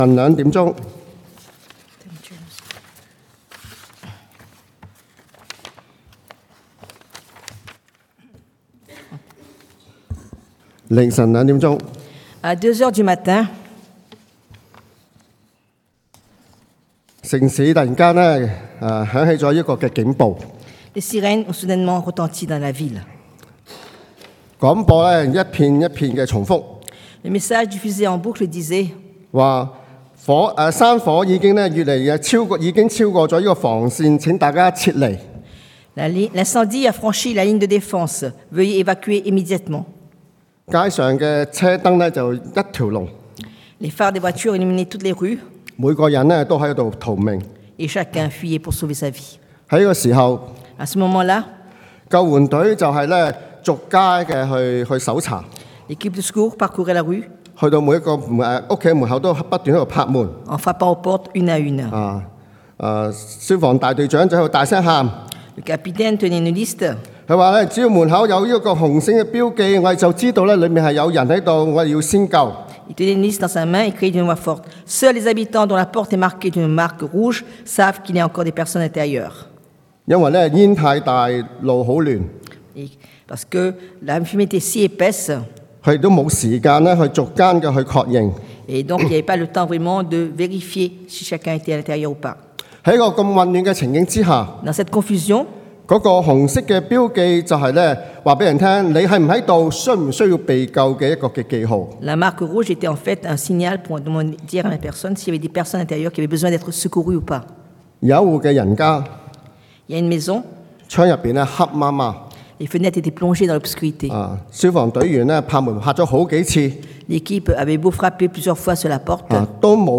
Predication du dimanche